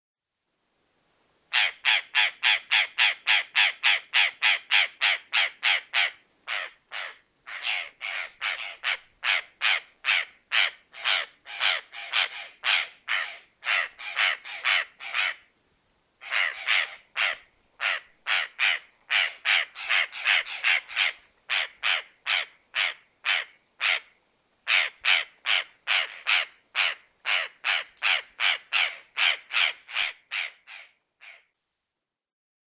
Cyanocorax cyanomelas - Urraca morada
Cyanocorax cyanomelas.wav